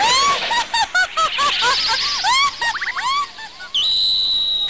1 channel
Ranma95_ahahaha.mp3